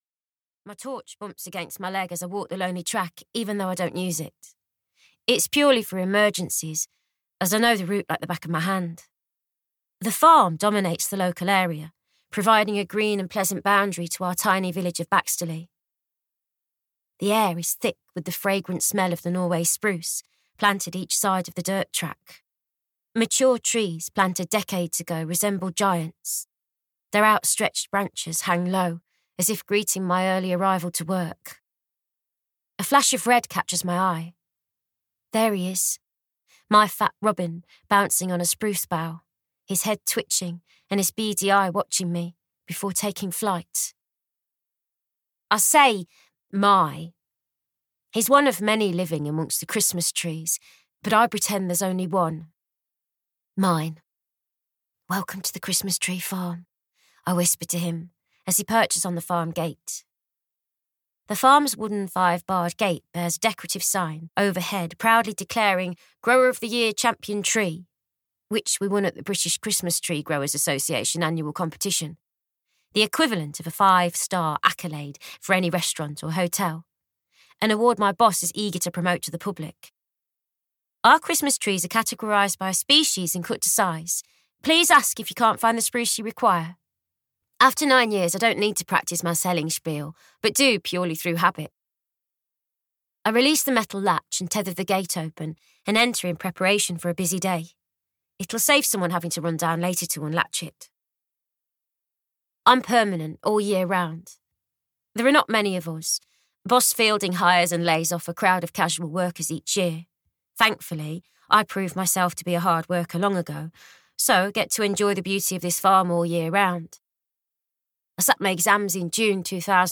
The Magic of Christmas Tree Farm (EN) audiokniha
Ukázka z knihy